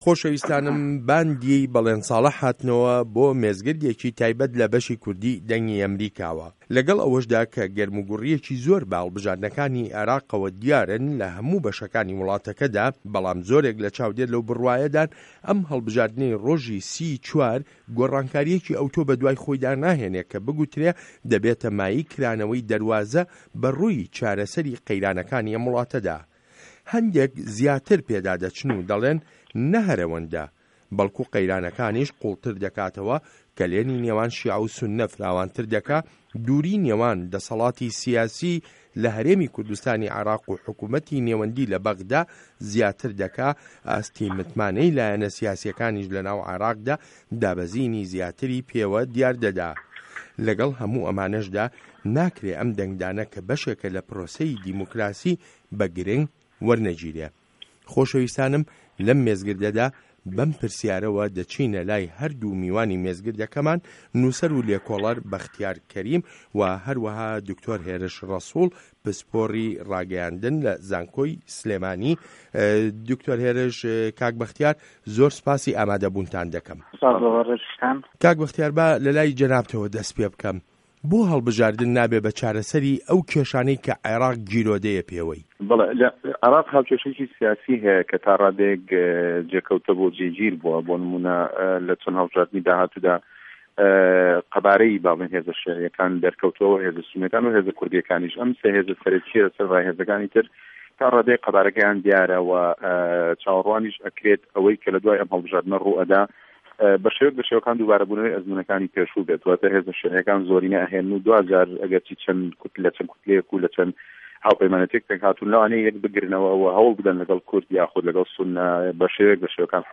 مێزگرد: هه‌ڵبژاردنه‌کانی عێراق